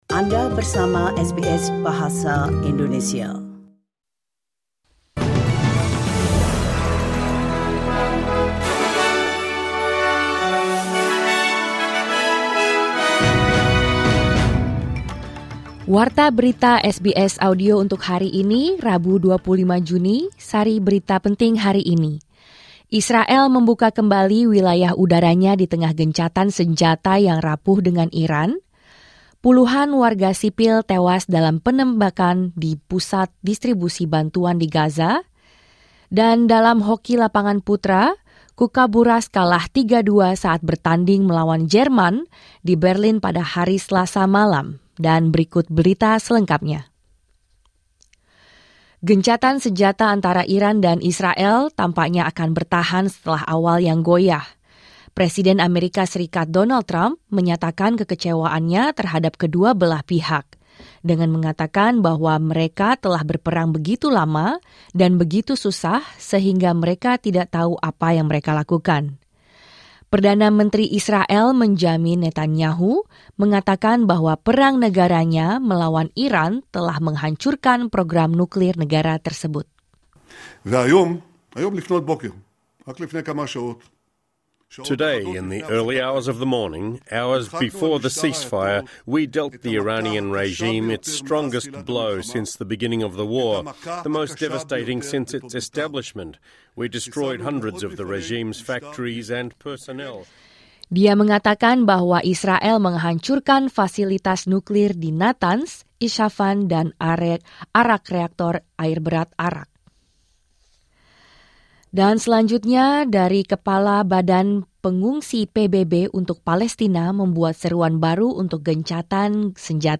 Berita Terkini SBS Audio Program Bahasa Indonesia - 25 Juni 2025